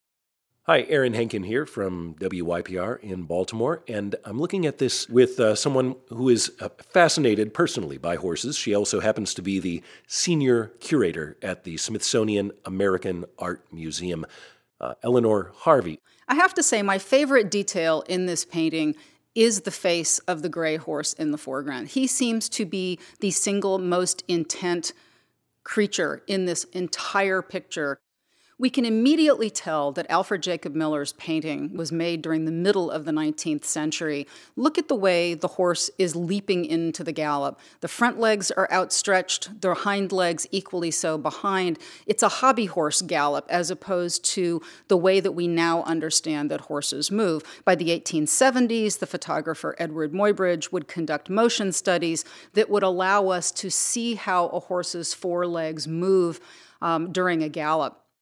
A Conversation about the Horses
A-conversation-about-the-horses-1.mp3